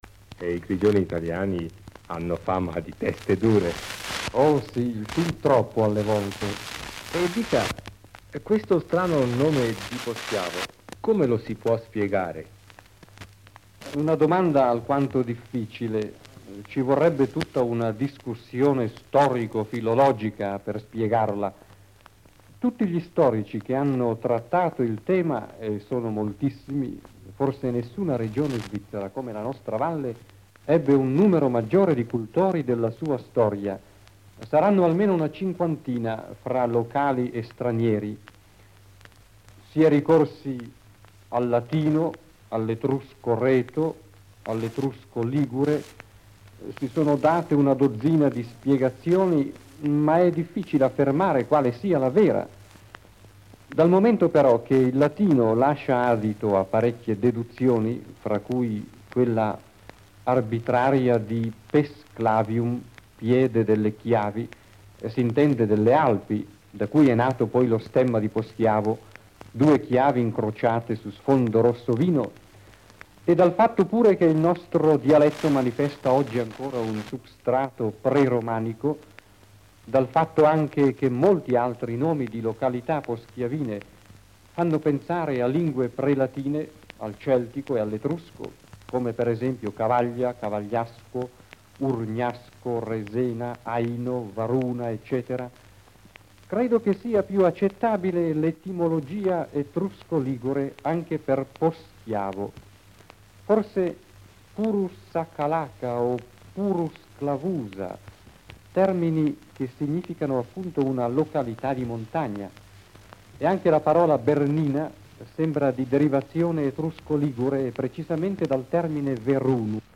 In questa intervista